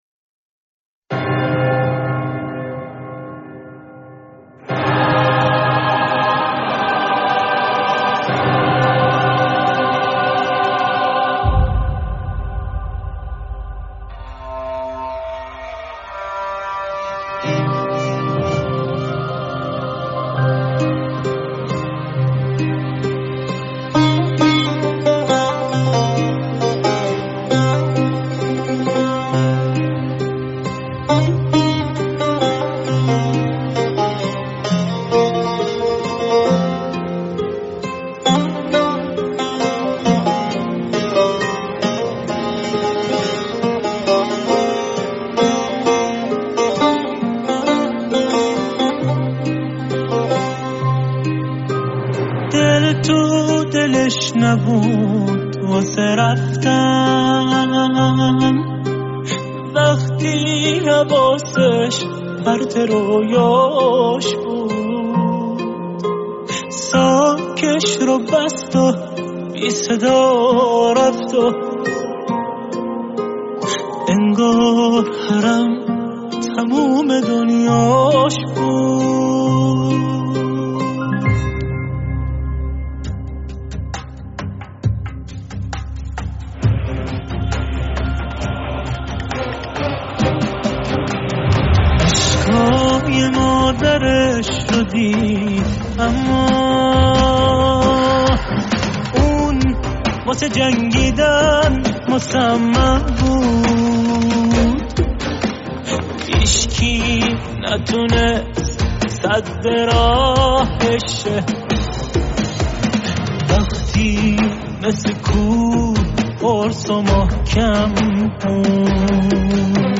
ترانه